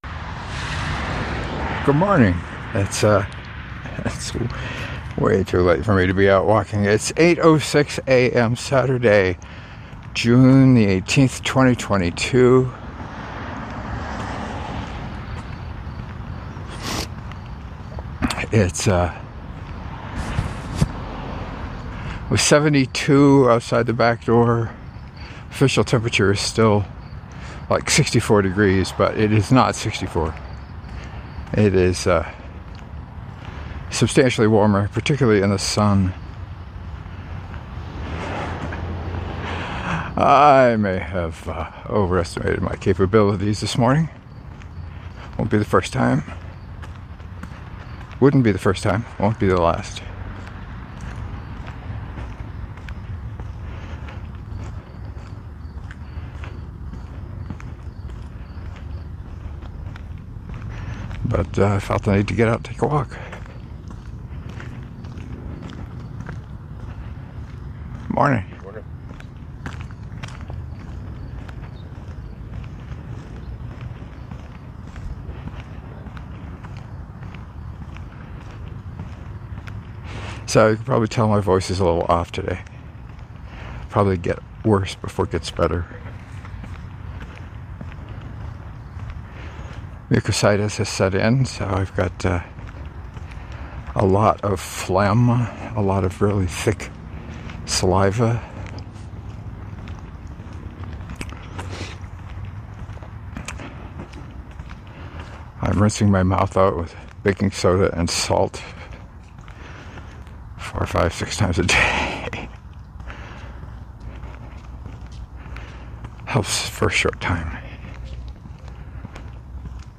Listening to it might not be pleasant.